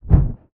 MAGIC_SPELL_Flame_03_mono.wav